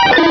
pokeemerald / sound / direct_sound_samples / cries / poliwhirl.aif